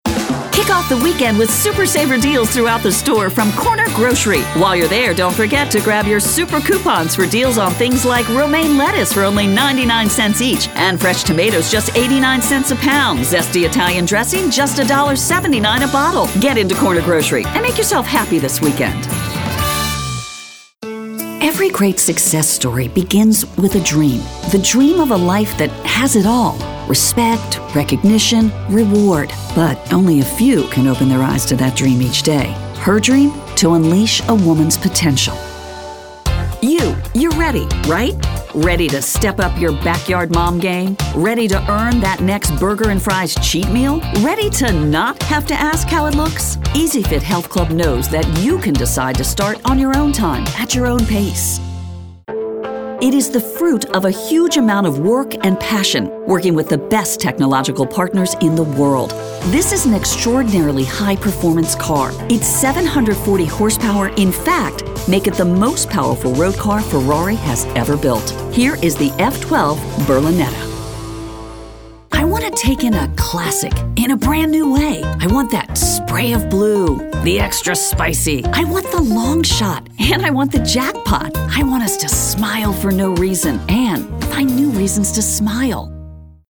announcer, caring, confessional, confident, conversational, friendly, girl-next-door, high-energy, inspirational, middle-age, perky, professional, promo, smooth, soccer mom, thoughtful, upbeat, warm